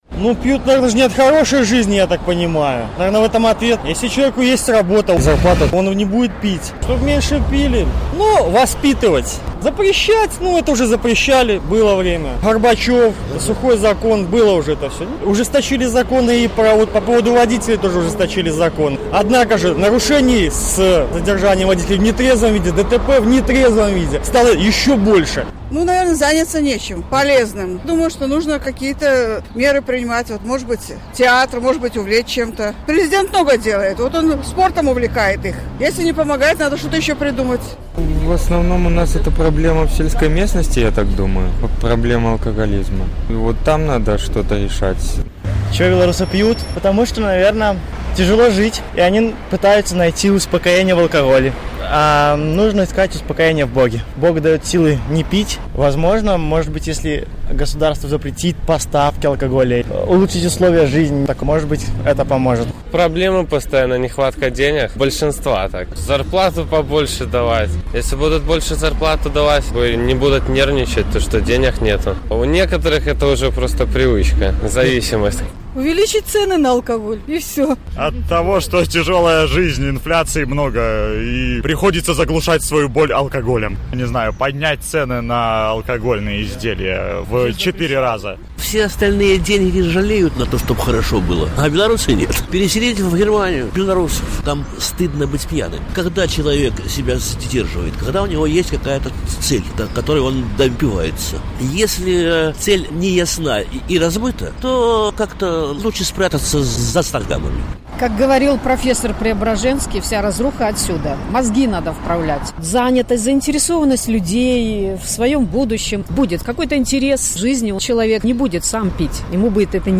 Што трэба зрабіць, каб беларусы меней пілі? На гэтае пытаньне адказваюць жыхары Гомеля